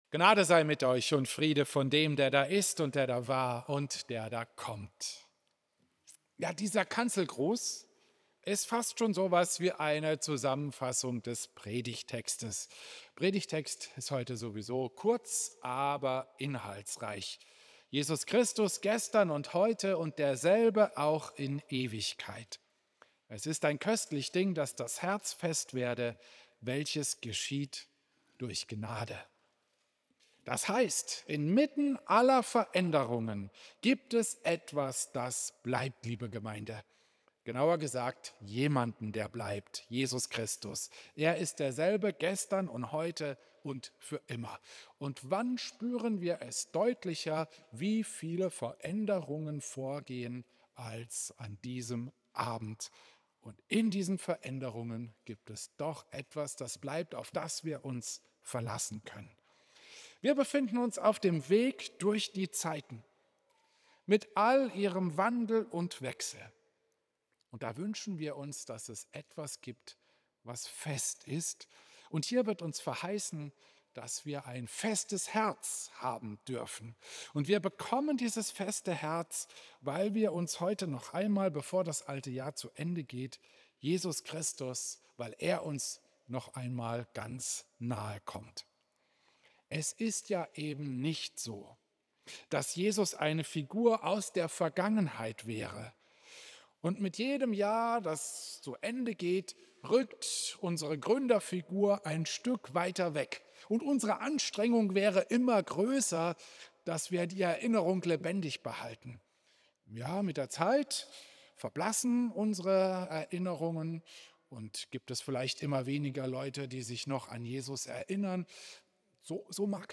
Klosterkirche Volkenroda, 31. Dezember 2025